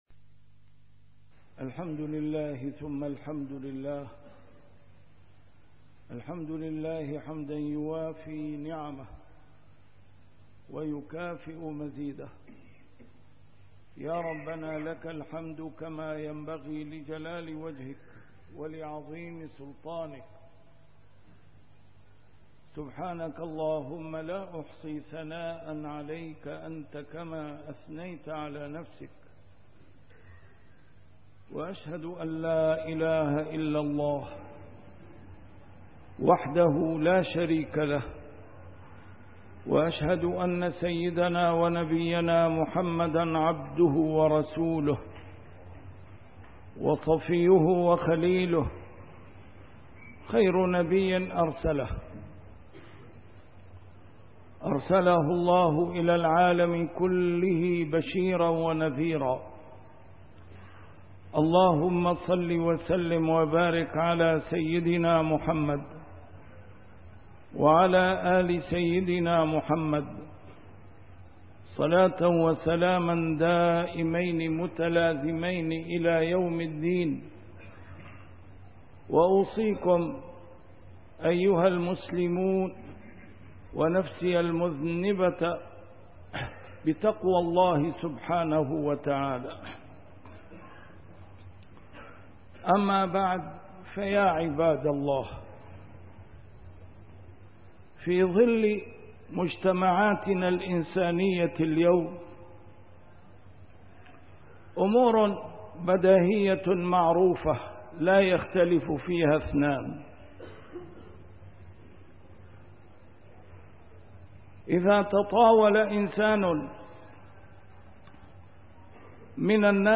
A MARTYR SCHOLAR: IMAM MUHAMMAD SAEED RAMADAN AL-BOUTI - الخطب - هل كُتب على إسلامنا أن يُيَتَّم؟